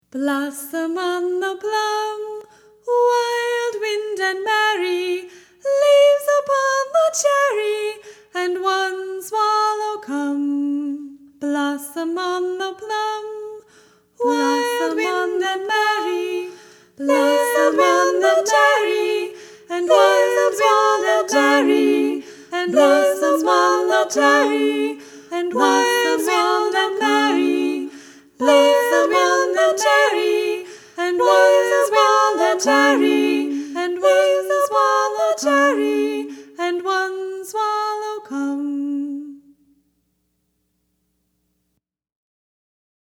If you are new to harmony, one of the easiest ways to drop right into it is to sing a round.